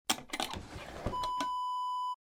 Dresser drawer open sound effect .wav #5
Description: The sound of a wooden dresser drawer being opened
Properties: 48.000 kHz 16-bit Stereo
A beep sound is embedded in the audio preview file but it is not present in the high resolution downloadable wav file.
Keywords: wooden, dresser, drawer, pull, pulling, open, opening
drawer-dresser-open-preview-5.mp3